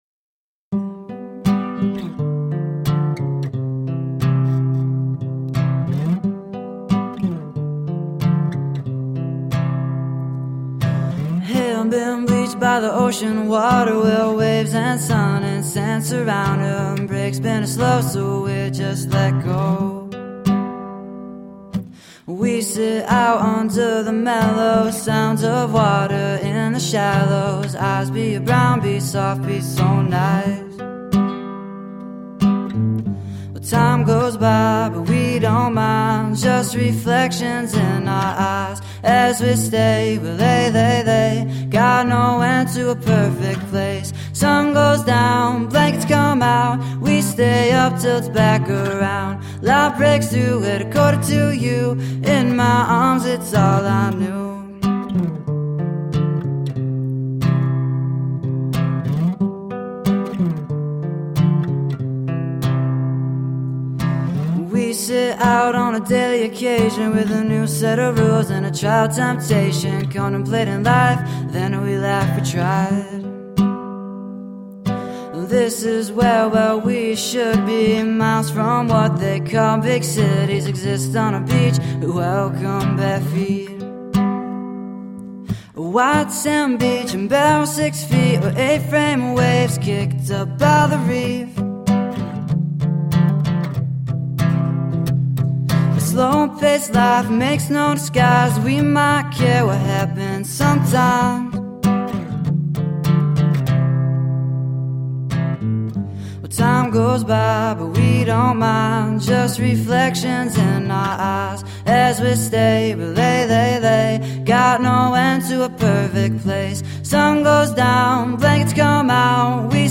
Guitar driven alternative rock.
Tagged as: Alt Rock, Folk-Rock, Chillout, Indie Rock